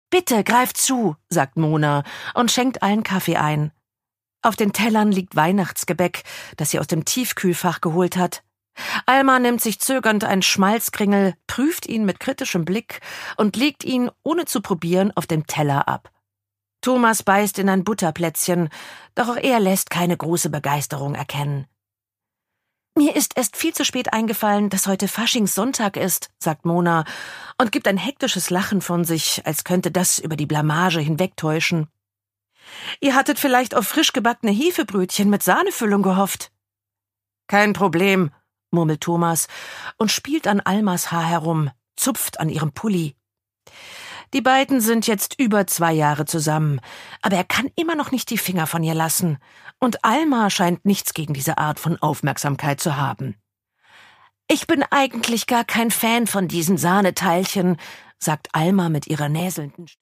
Line Baugstø: Evil Grandma (Ungekürzte Lesung)
Produkttyp: Hörbuch-Download